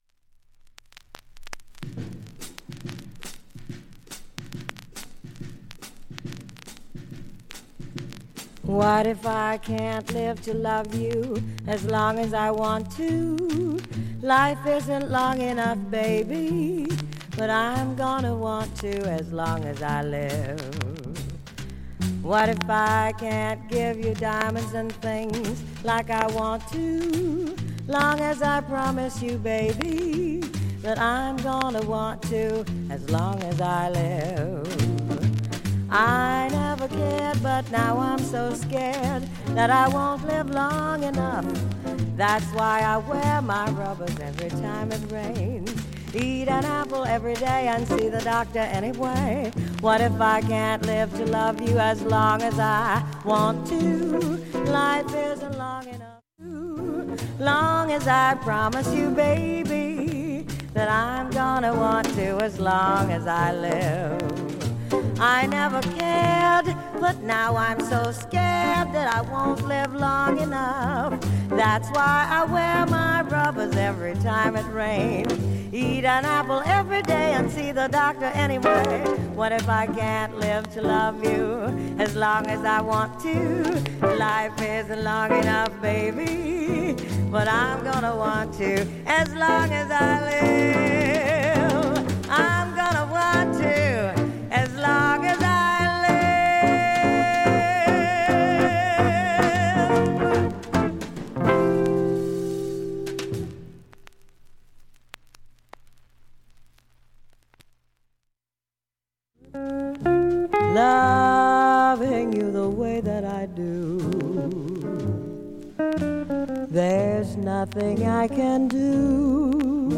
大半はチリも無く普通に聴けます
1,A-1始め53秒の間にチリプツ出ます。
2,(56s〜)A-1終盤に50秒かすかチリプツ
3,(1m48s〜)A-2後半に55秒かすかチリプツ
4,(2m40s〜)A-3中盤にかすか41回プツ
/VAN GELDER STEREOマシン刻印両面